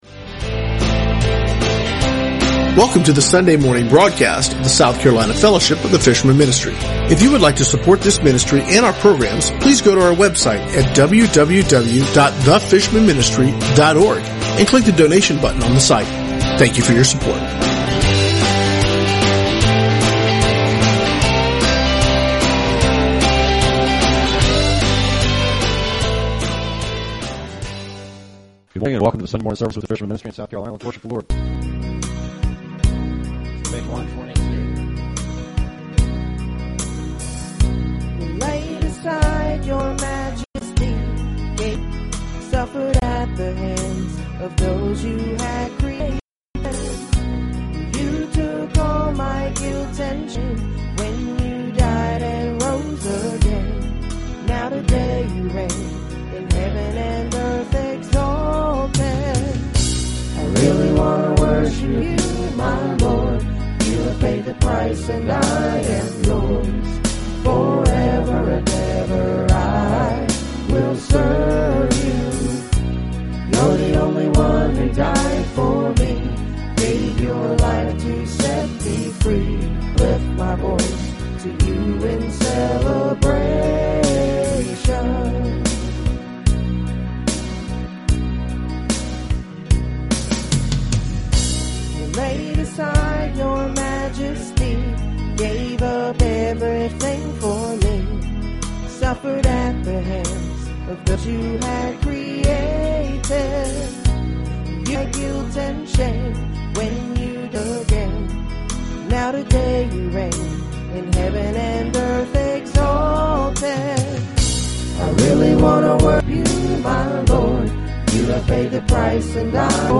Sunday South Carolina Church Service 05/15/2016 | The Fishermen Ministry